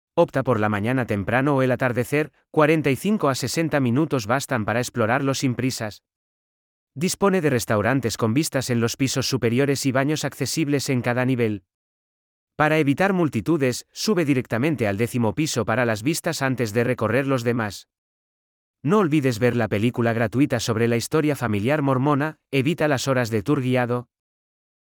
🎧 Guías de audio disponibles (2) Guía de Experiencia Emocional (ES) 🔗 Abrir en una nueva pestaña Información práctica (ES) 🔗 Abrir en una nueva pestaña